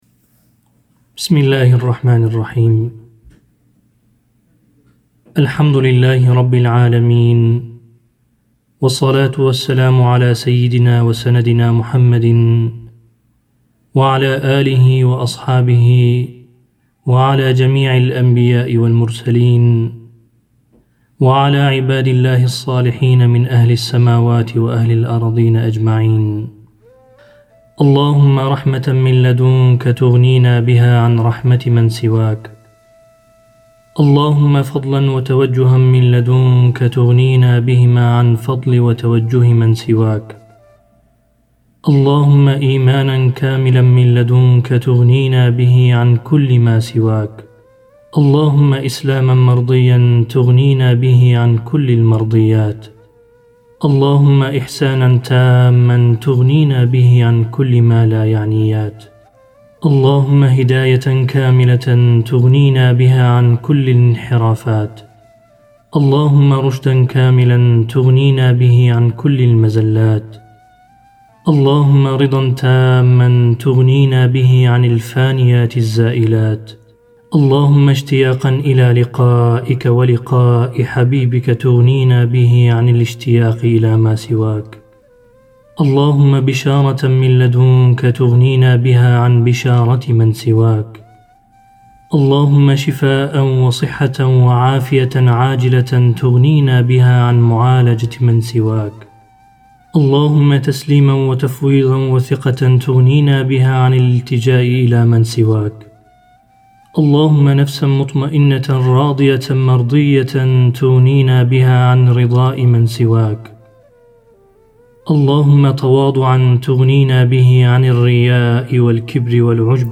“TEVHİDNÂME” görseli ve seslendirmesi ile yenilendi.